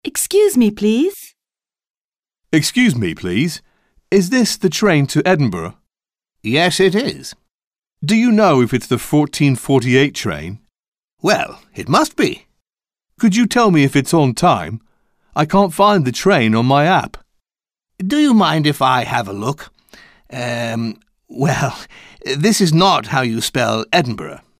Dialogue - Excuse me, please...